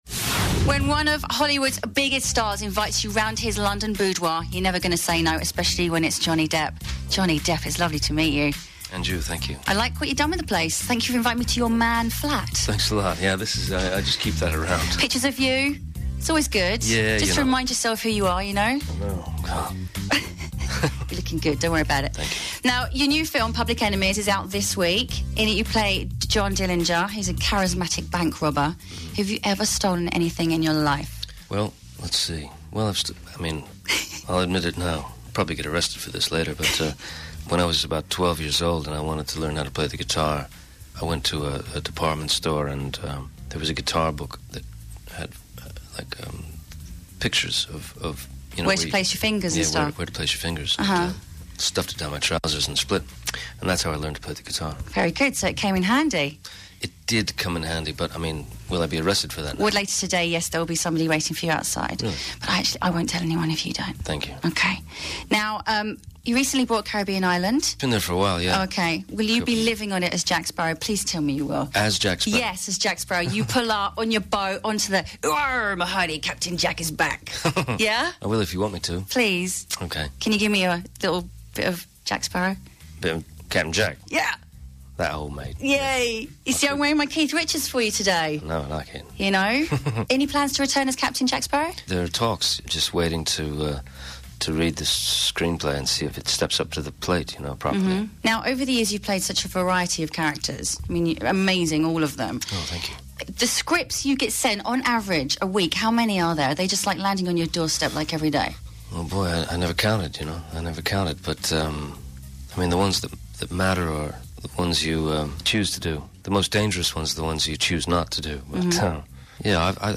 Public Enemies Radio Interview